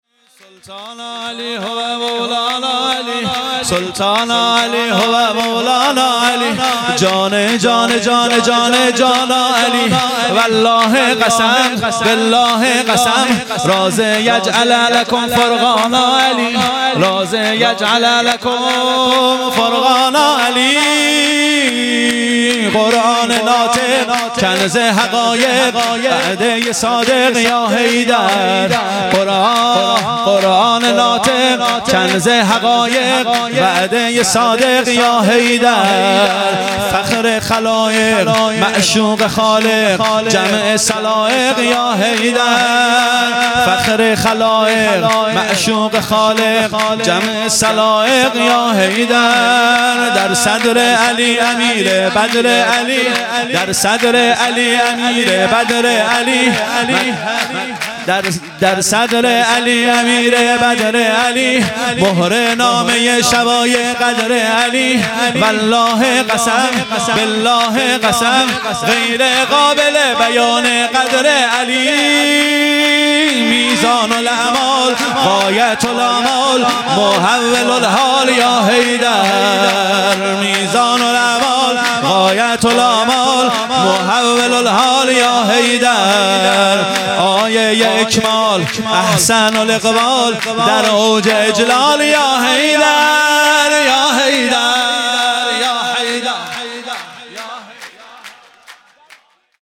سرود
جشن عید غدیر